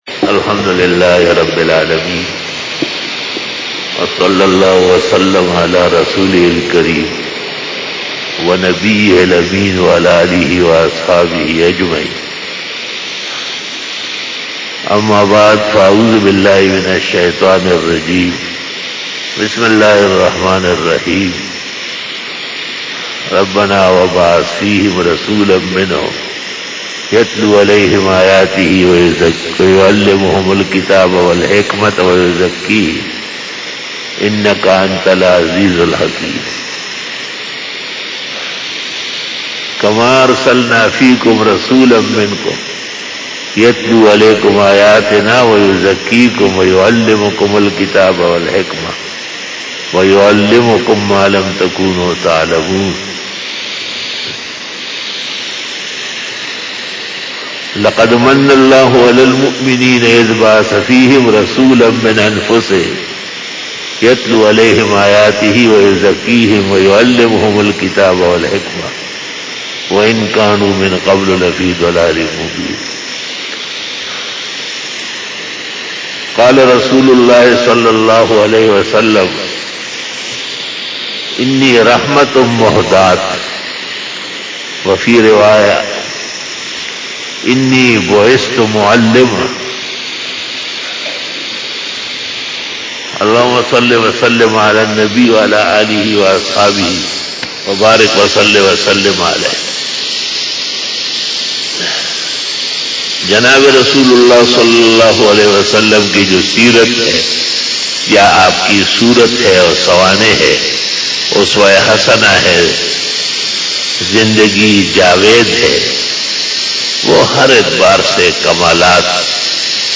39 BAYAN E JUMA TUL MUBARAK 06 November 2020 (19 Rabi ul Awwal 1442H)